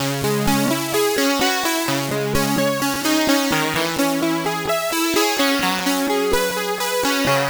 Epic Pad Cm 128.wav